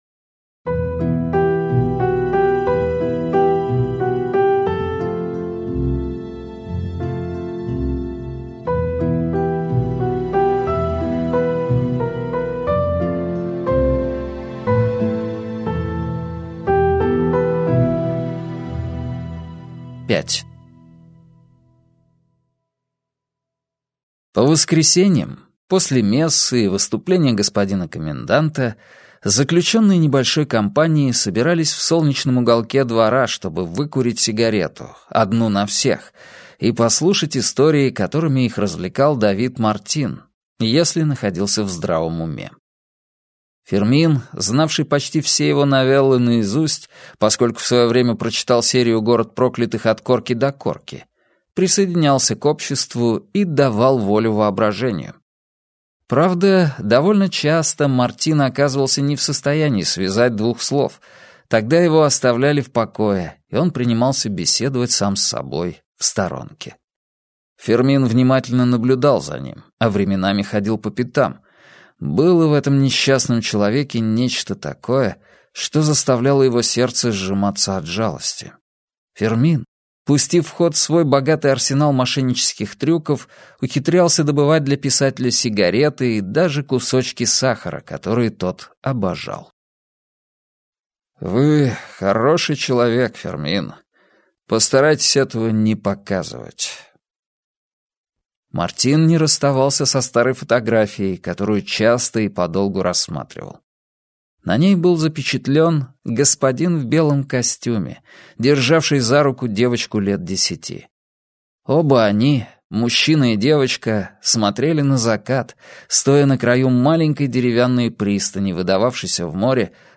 Аудиокнига Узник неба - купить, скачать и слушать онлайн | КнигоПоиск